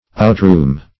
outroom - definition of outroom - synonyms, pronunciation, spelling from Free Dictionary
outroom - definition of outroom - synonyms, pronunciation, spelling from Free Dictionary Search Result for " outroom" : The Collaborative International Dictionary of English v.0.48: Outroom \Out"room`\, n. An outer room.